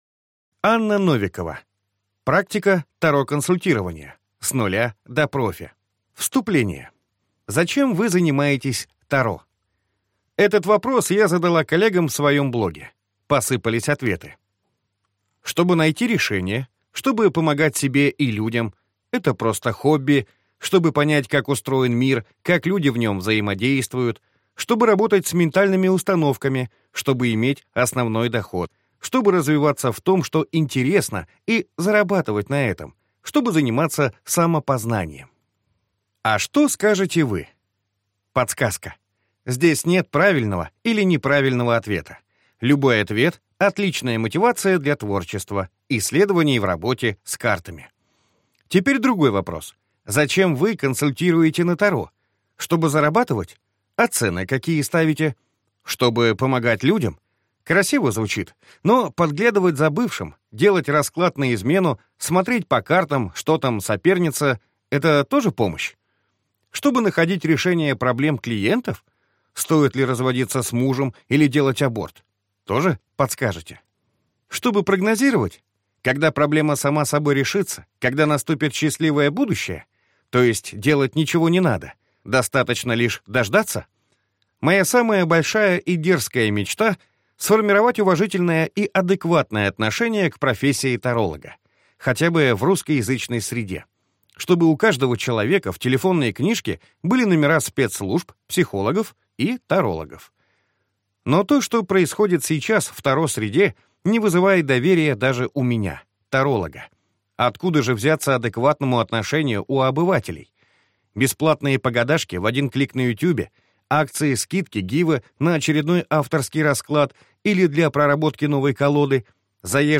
Аудиокнига Практика Таро-консультирования. С нуля до профи | Библиотека аудиокниг